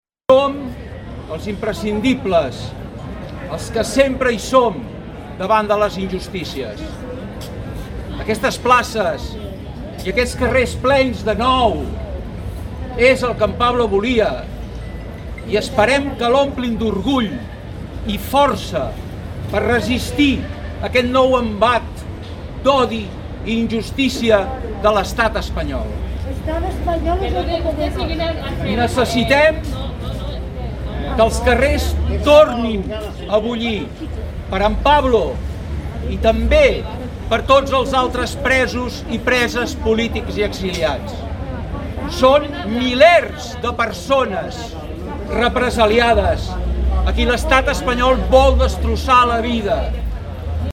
Més d'un centenar de persones es concentraven al crit de Llibertat Pablo Hasél davant la porxada de l'Ajuntament de Cerdanyola contra l'empresonament del músic de rap condemnat a nou mesos de presó, sis anys d'inhabilitació i prop de 30.000 euros de multa per delictes d'enaltiment del terrorisme i injúries contra la Corona i institucions de l'Estat.
Fragment del manifest llegit